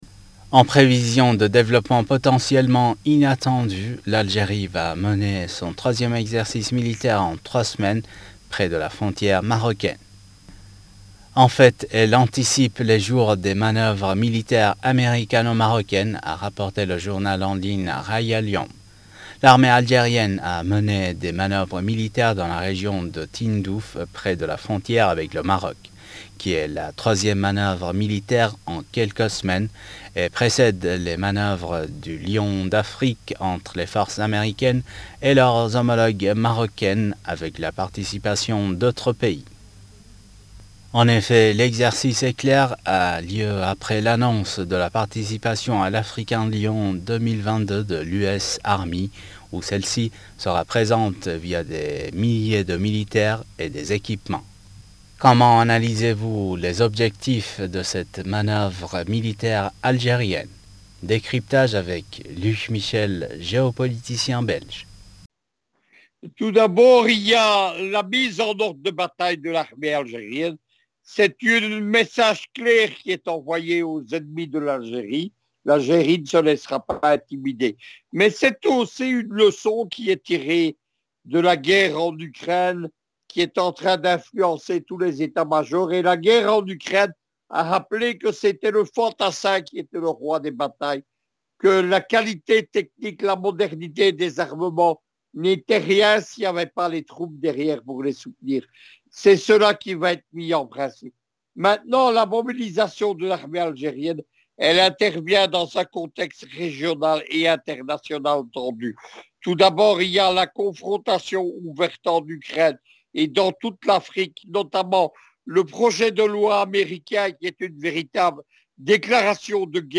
géopoliticien belge